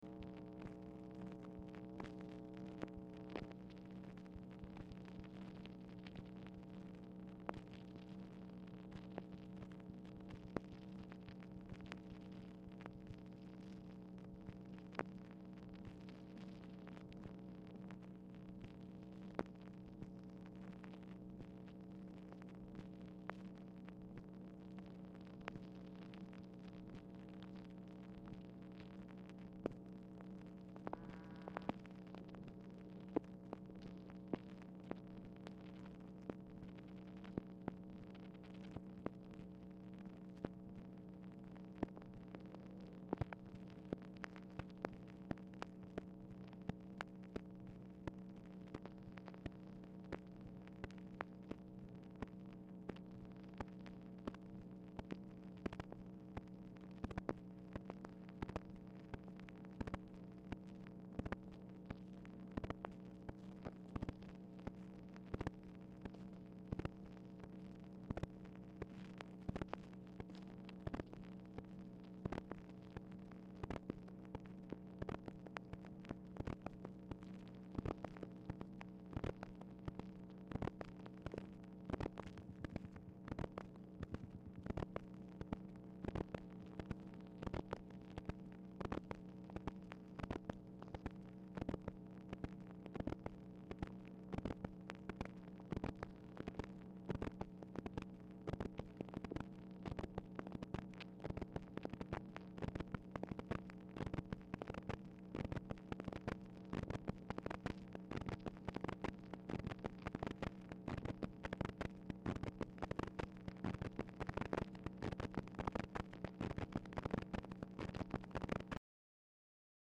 Telephone conversation # 5665, sound recording, MACHINE NOISE, 9/24/1964, time unknown | Discover LBJ
Format Dictation belt
Speaker 2 MACHINE NOISE Specific Item Type Telephone conversation